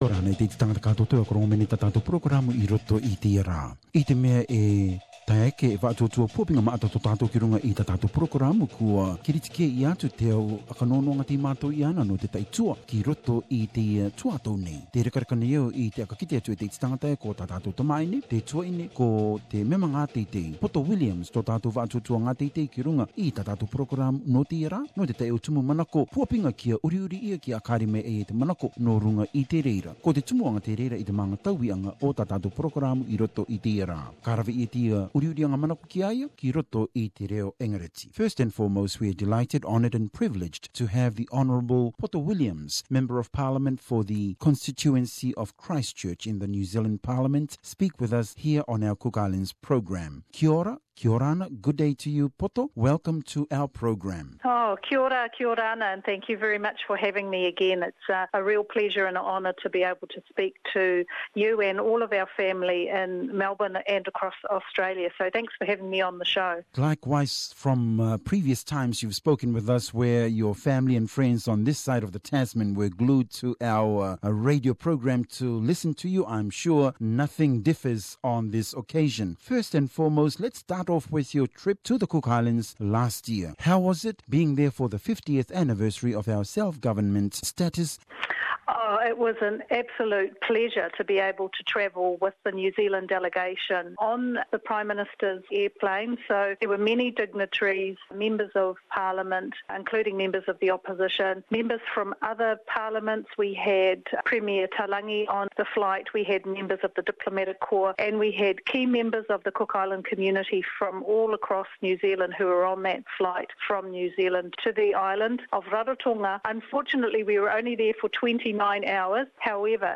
Hon Poto Williams Interview
Hon Poto Williams, Christchurch MP in the New Zealand Parliament.